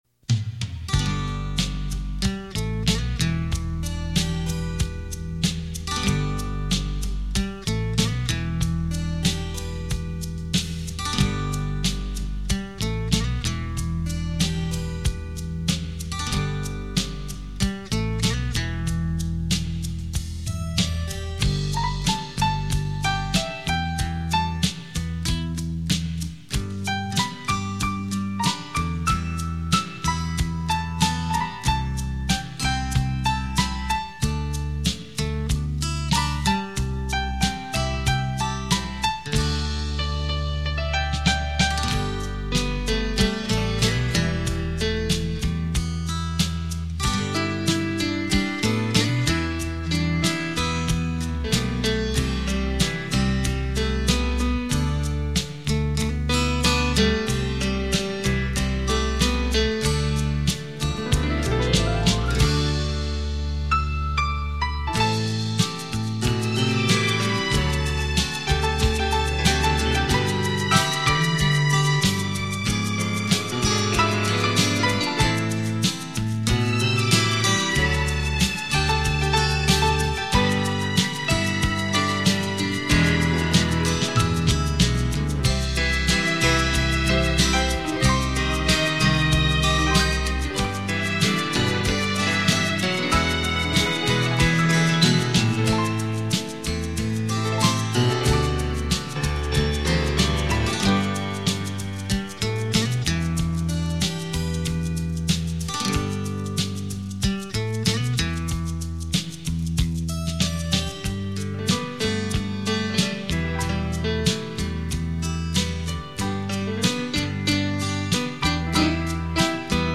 经典演奏曲
双钢琴的震撼，与您体验琴乐声交织而成的文化艺术飨宴。